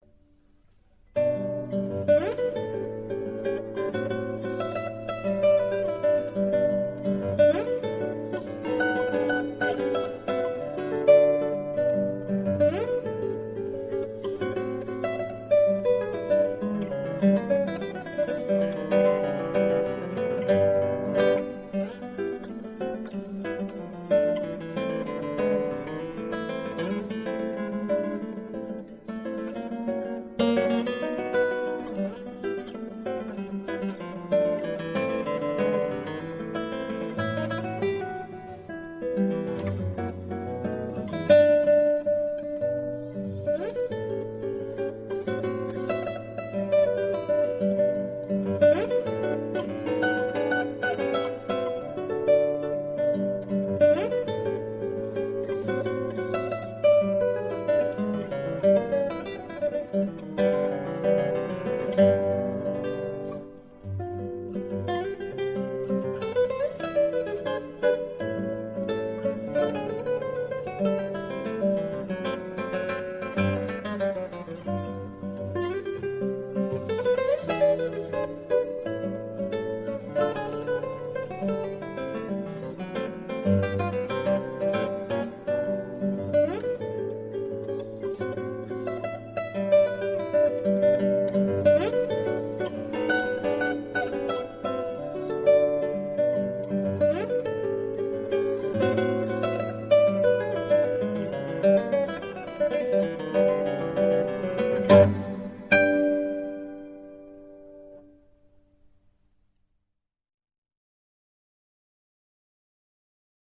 0133-吉他名曲波黛米科.mp3